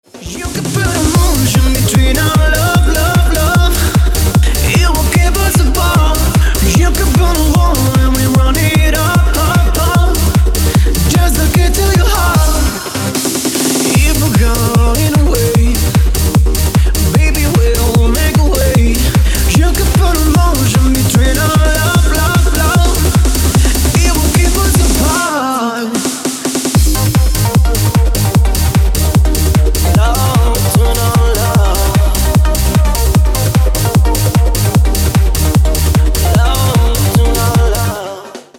Workout Mix Edit 150 bpm